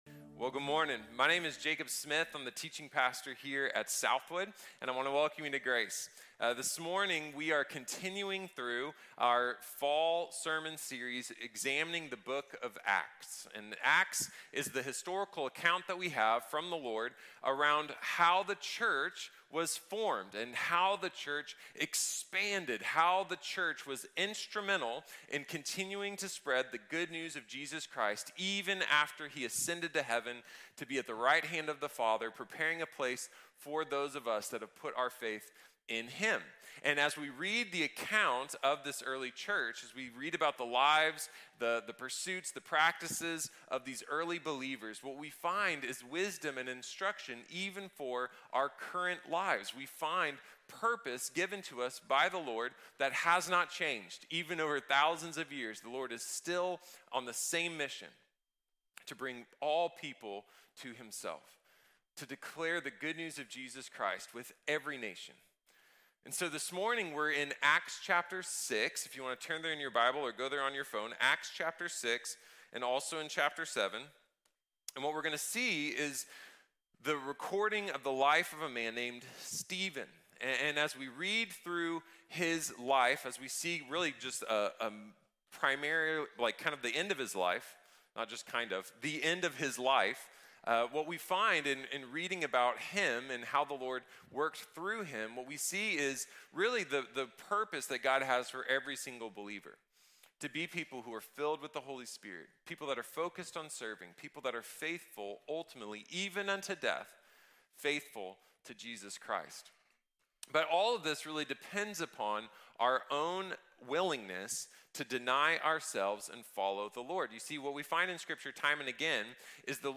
Stephen | Sermon | Grace Bible Church